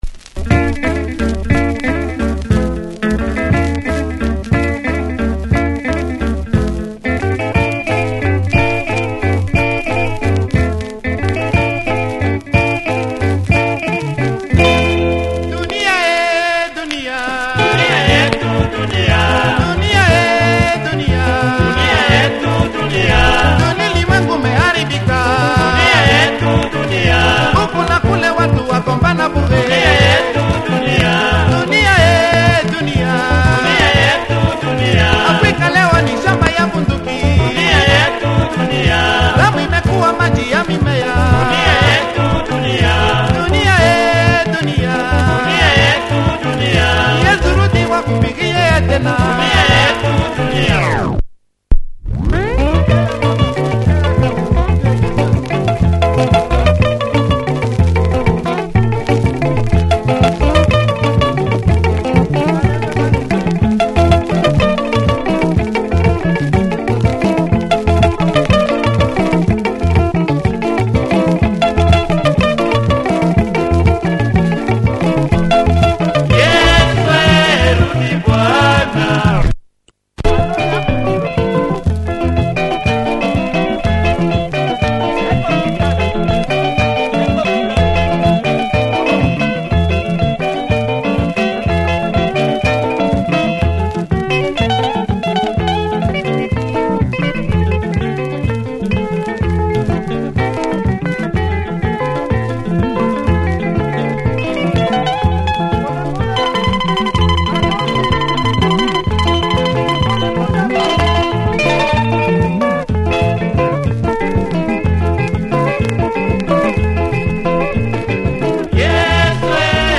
Swahili rumba
Some super guitar work on part-2!